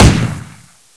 ump45-1.wav